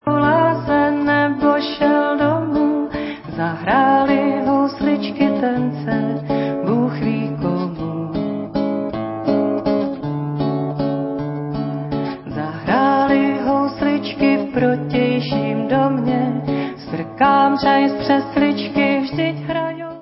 Záznam koncertu